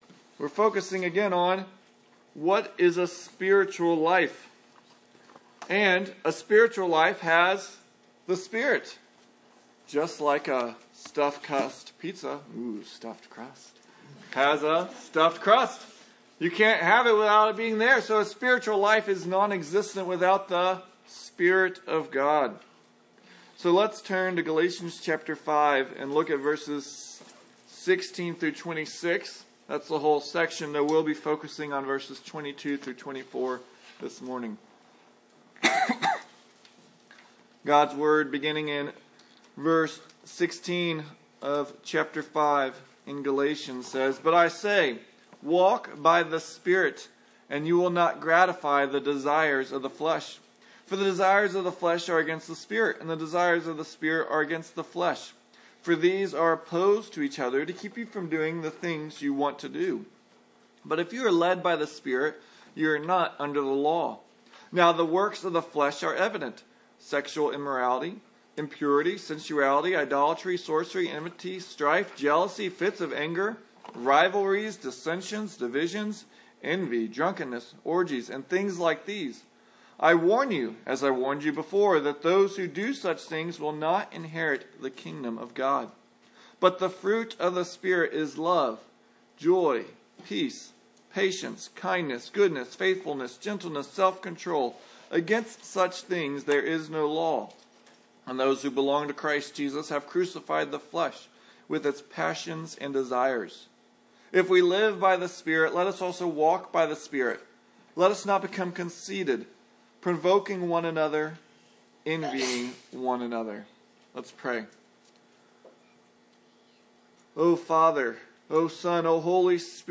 Passage: Galatians 5:16-26 Service Type: Sunday Morning